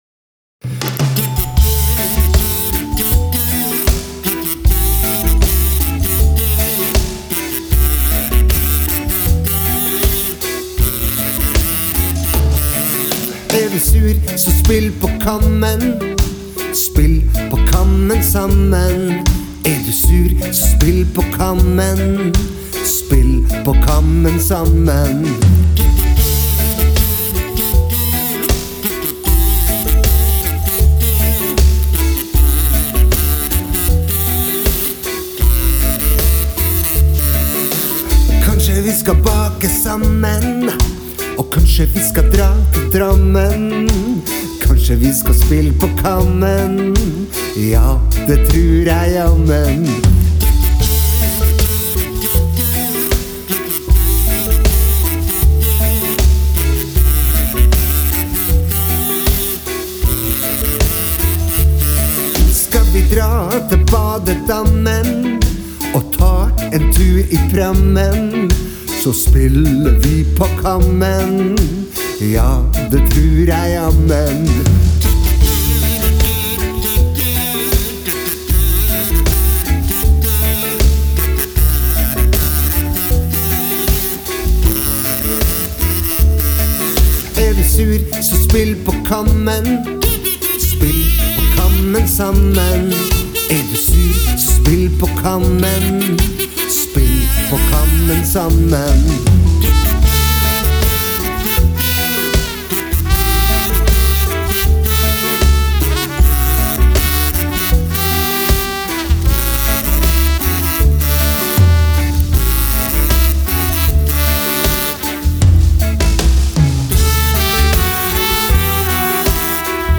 Mandag 17. januar 2022: SPILL PÅ KAMMEN SAMMEN – BARNESANG (Sang nr 138 – på 138 dager)
Tekst og melodi + kazoo
Sangen låter bedre, det samme med kazooen. Skarptromma har litt mer trykk og jeg har fjernet overhøringa mellom tam-slagene. xylofonen er litt høyere. Jeg har også autotunet sangen …)